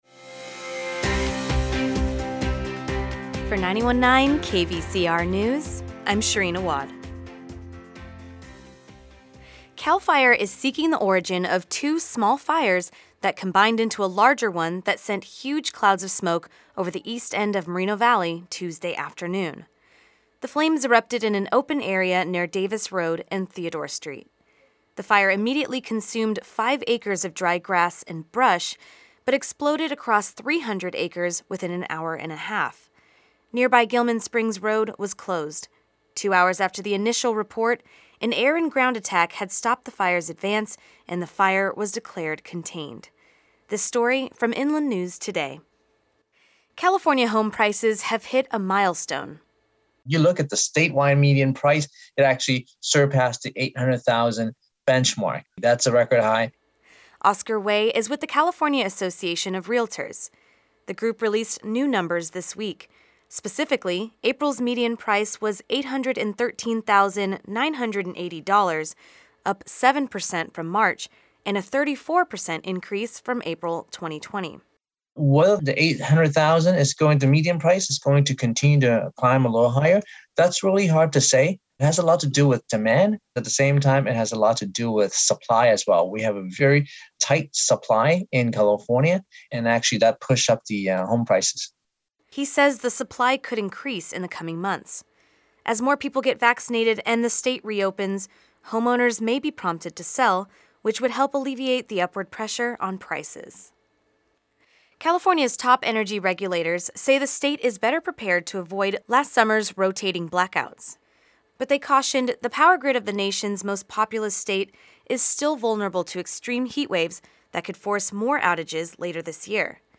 The Midday News Report
Monday through Thursday at lunchtime, KVCR News has your daily news rundown.